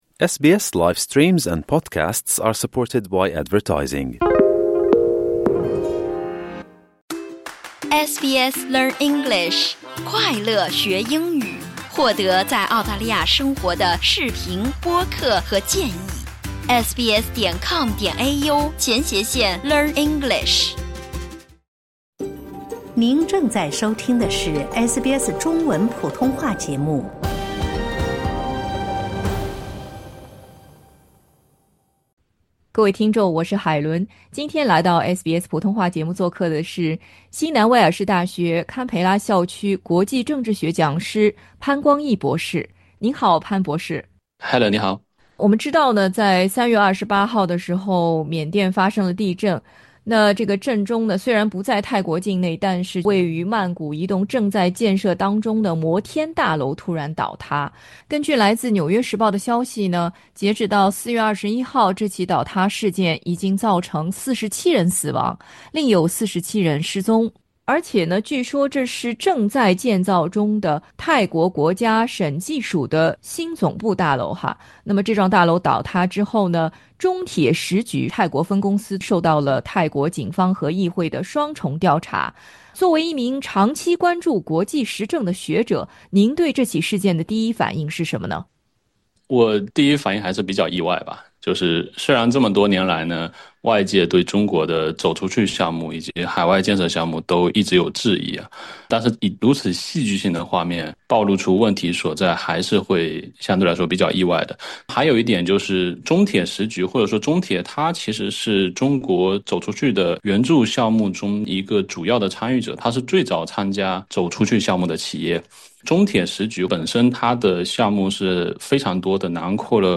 此次事件为国际合作项目敲响了警钟，如何建立更为有效的跨文化沟通机制、统一质量标准以及强化监管执行，已成为国际合作项目中亟待解决的重要课题。 （采访内容仅为专家观点，不代表本台立场） 欢迎下载应用程序SBS Audio，关注Mandarin。